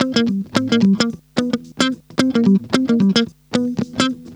Funk Master Guitar 02.wav